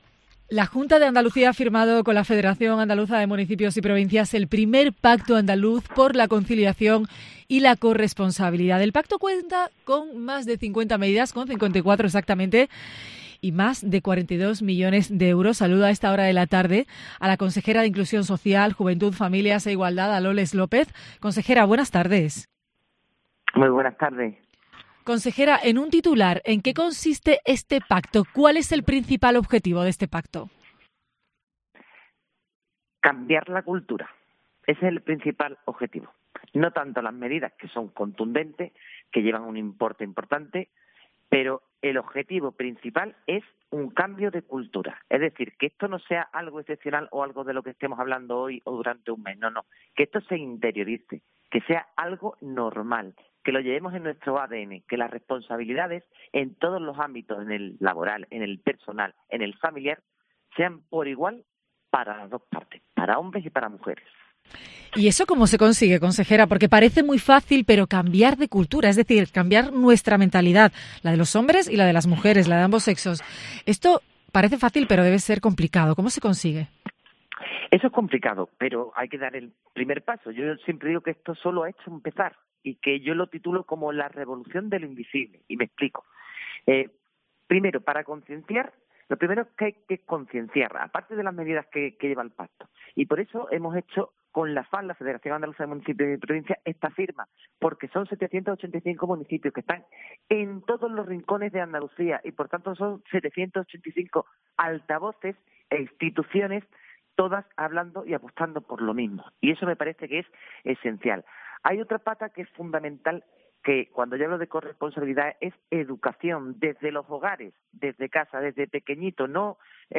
Loles López defiende en COPE la igualdad en el Pacto por la conciliación: "La revolución invisible"
En COPE Andalucía hemos hablado con la Consejera de Inclusión Social, Juventud, Familias e Igualdad , Loles López.